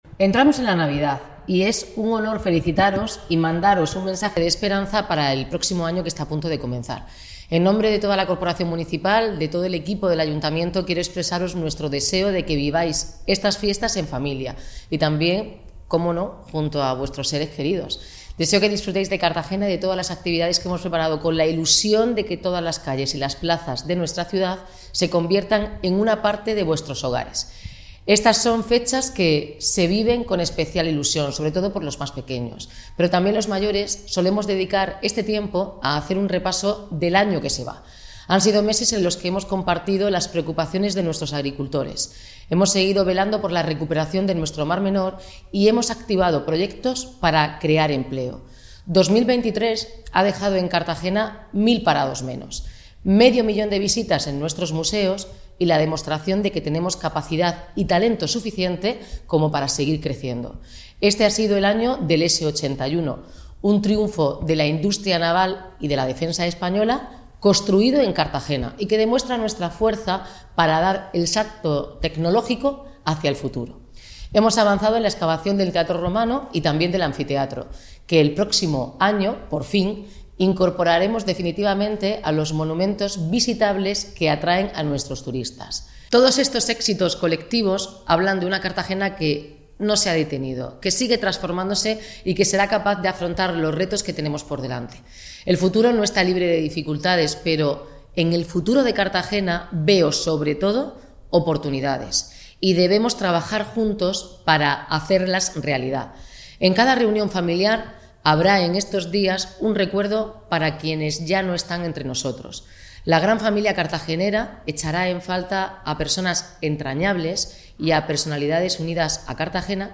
Enlace a Mensaje de la alcaldesa, Noelia Arroyo.